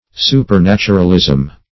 Supernaturalism \Su`per*nat"u*ral*ism\, n.